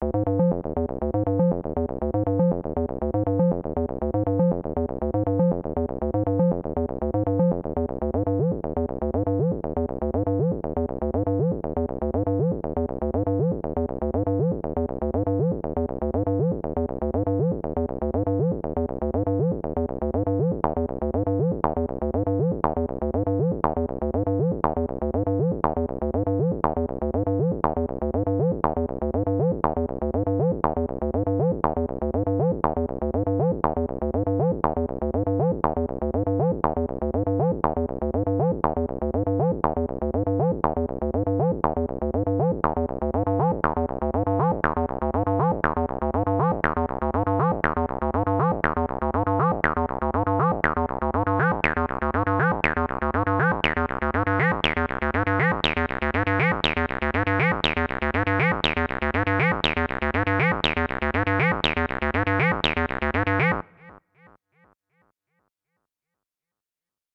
Not claiming I can make it sound like the original. But acid it can.